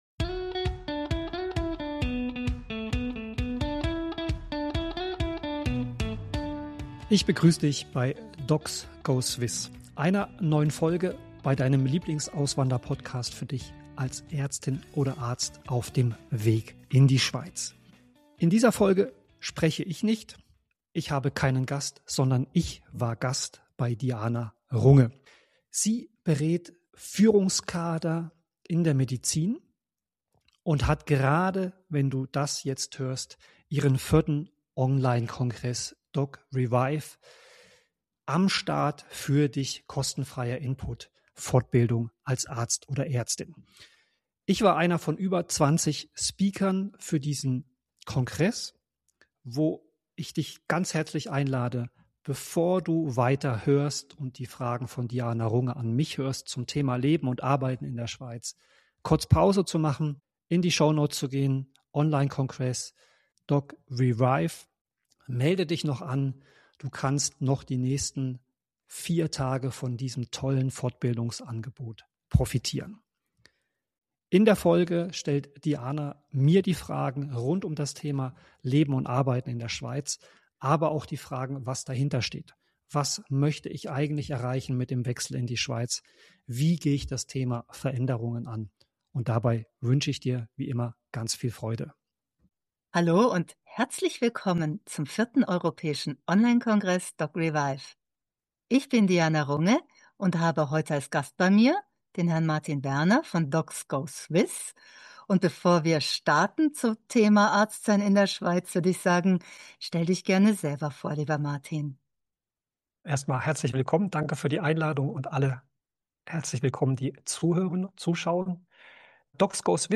Normalerweise stelle ICH die Fragen. Diesmal war ich Gast.